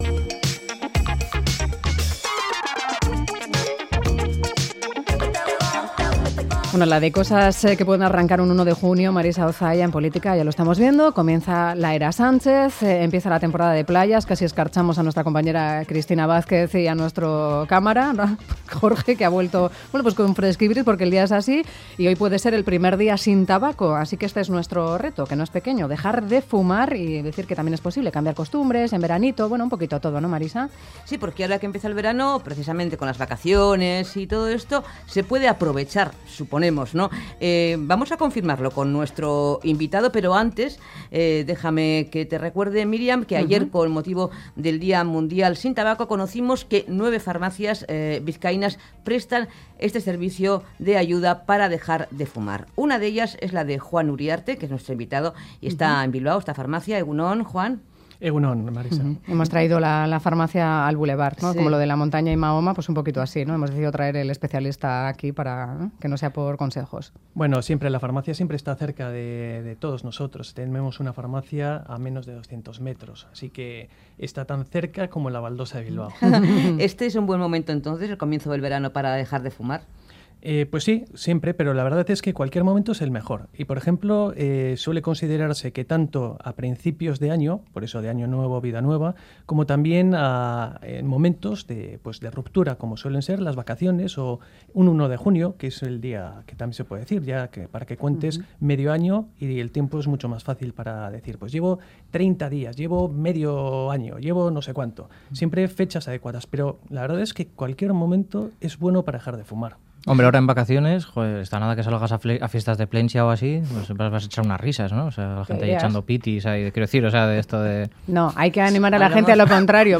Lo comprobamos con el testimonio de una paciente.